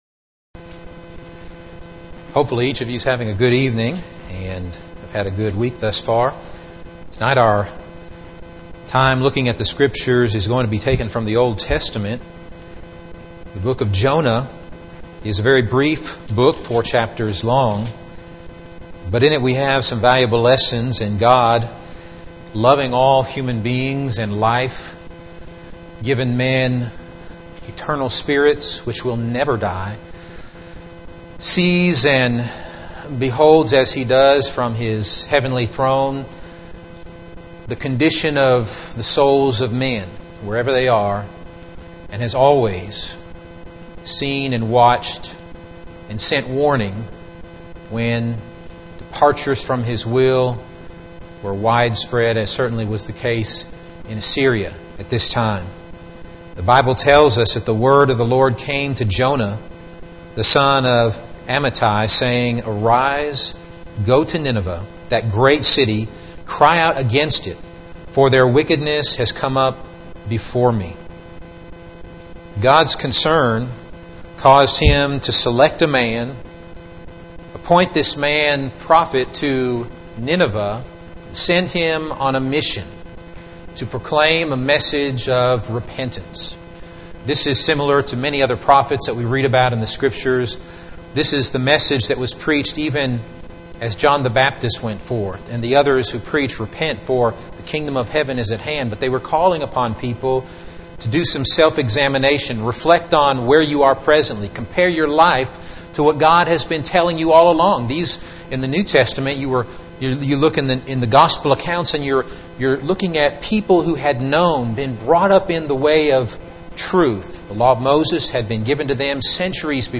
Midweek Bible Class « Youth Service